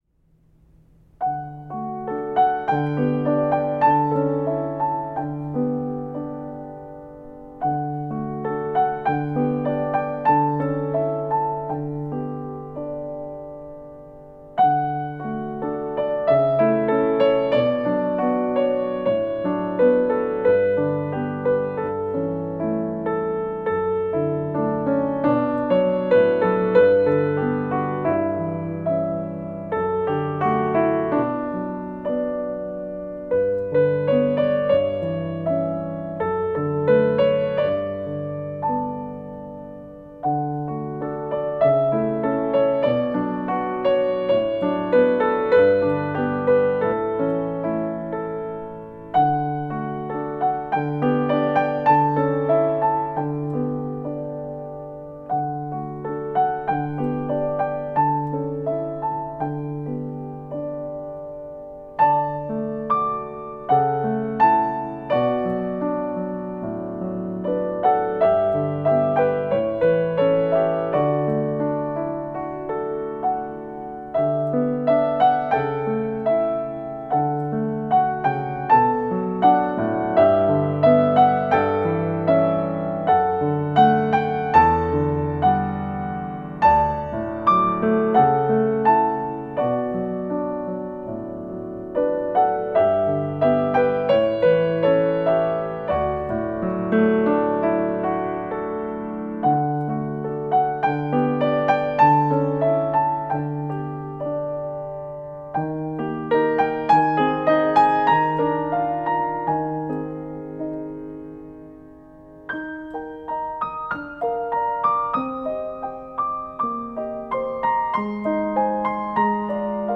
It begins in B-flat major and modulates to B major.
The recording below includes only the piano part.